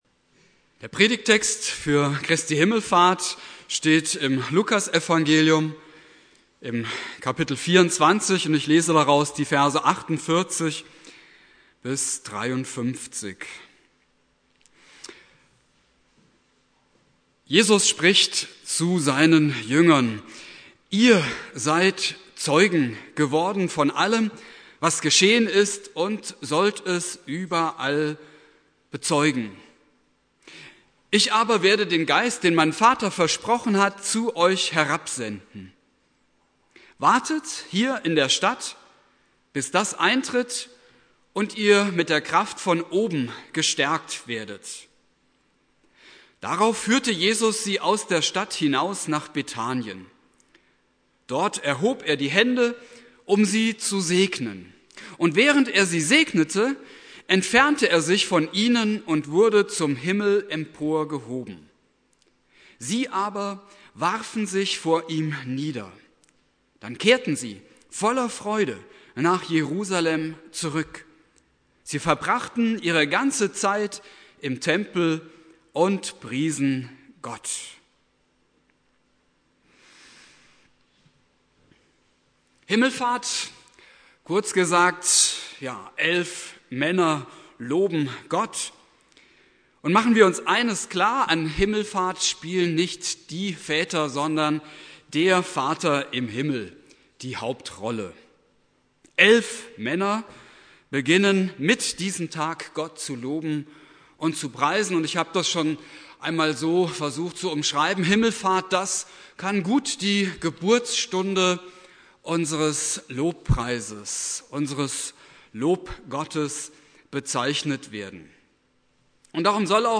Predigt
Christi Himmelfahrt Prediger